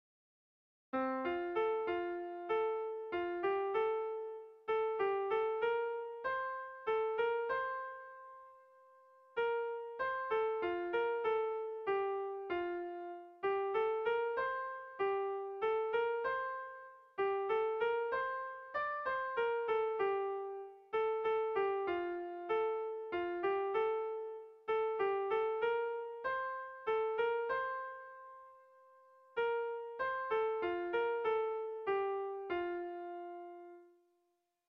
Air de bertsos - Voir fiche   Pour savoir plus sur cette section
Zortzi puntuko berdina, 8 silabaz
ABD.